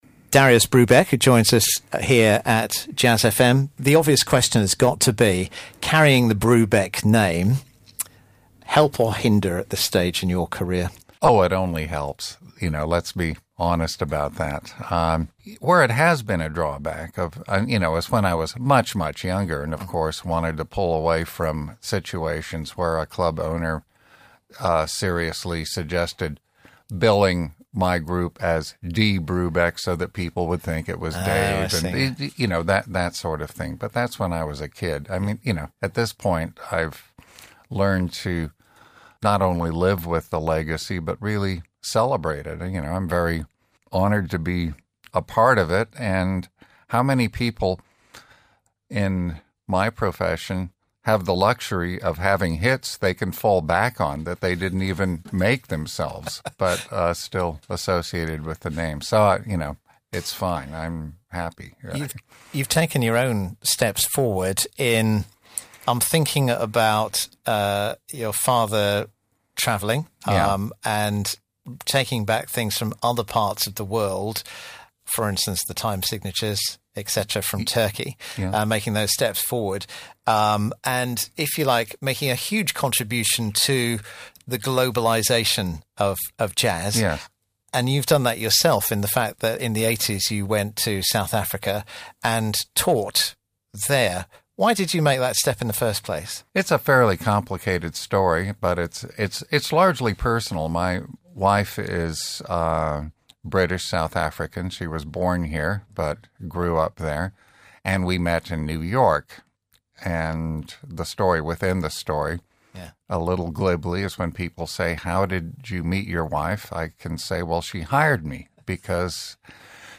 Darius Brubeck in conversation